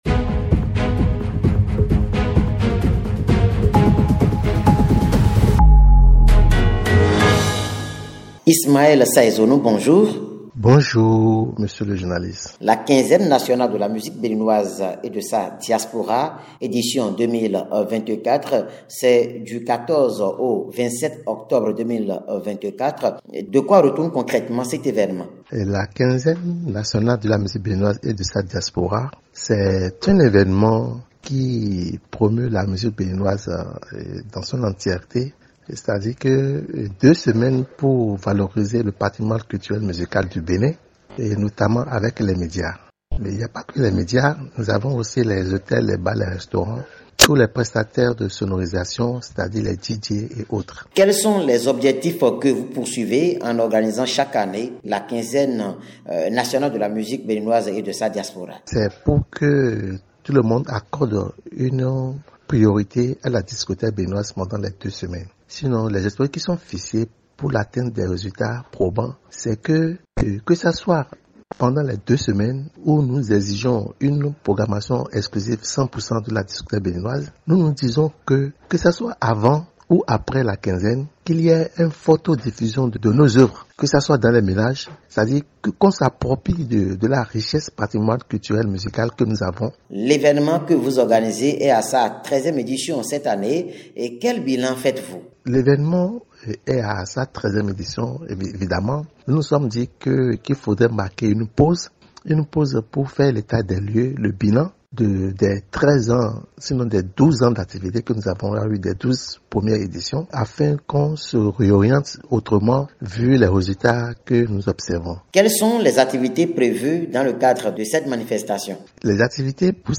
Invité du Journal